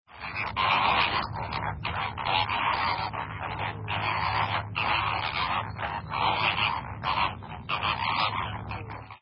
flamingoes
flamingo.mp3